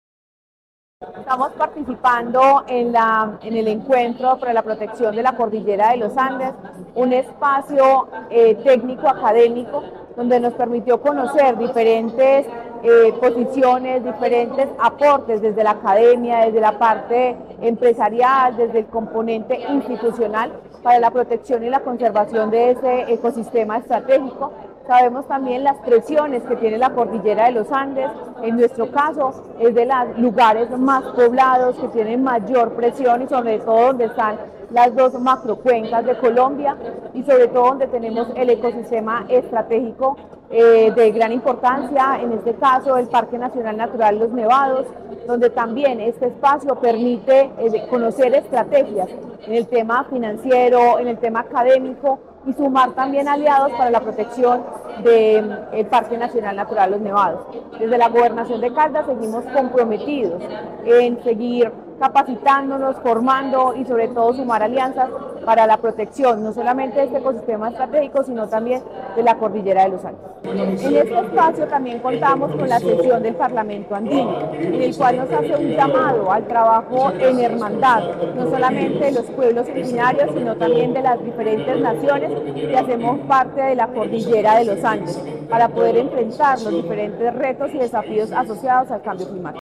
Paola Andrea Loaiza Cruz, secretaria de Medio Ambiente de Caldas
secretaria-Medio-Ambiente.mp3